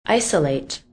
Watch out! You may see this word with another pronunciation and its meaning is then different.